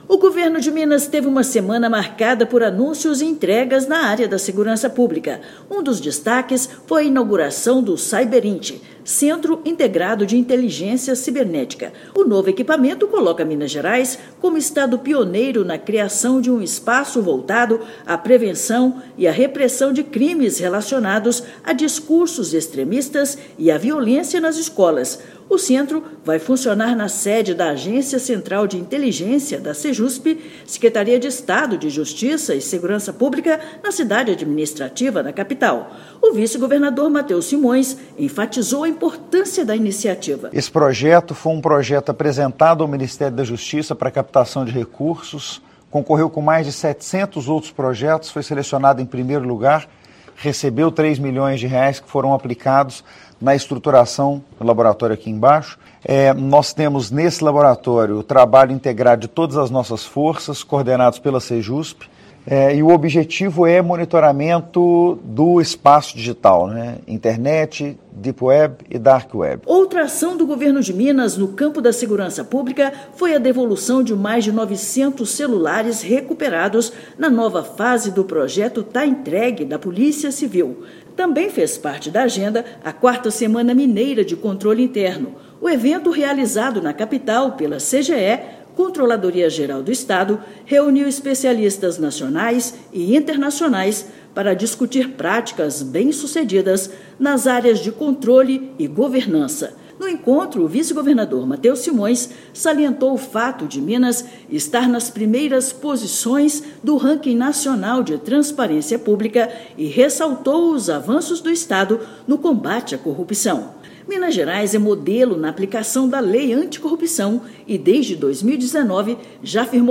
Entre as iniciativas: a inauguração do Centro Integrado de Inteligência Cibernética e a devolução de mais de 900 celulares recuperados pela Polícia Civil. Ouça matéria de rádio.
BOLETIM_SEMANAL_-_GOVERNO_DE_MINAS.mp3